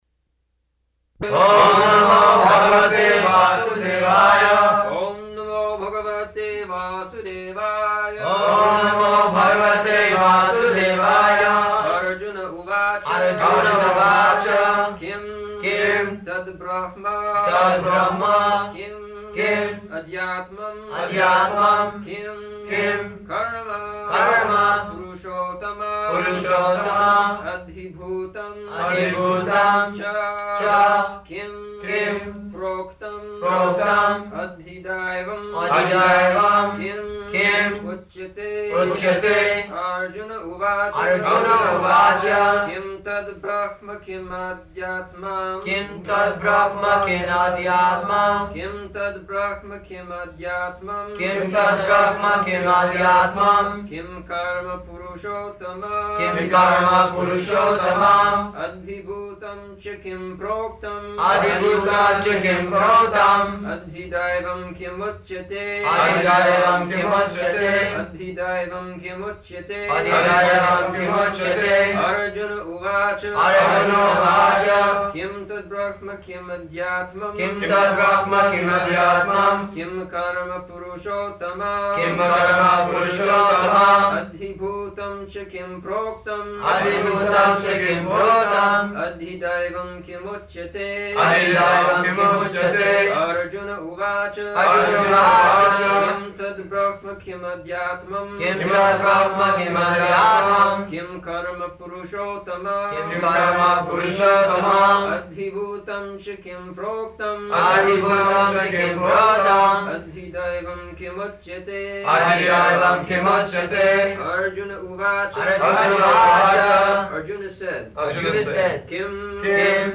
June 7th 1974 Location: Geneva Audio file
[devotees repeat] [leads chanting of verse, etc.]